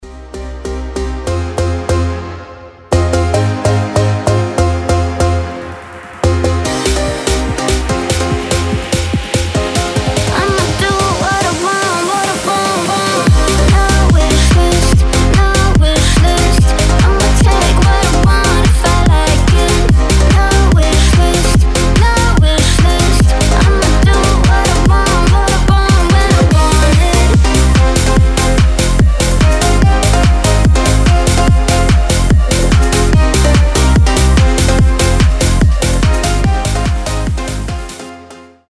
громкие
EDM
энергичные
быстрые
динамичные
organ house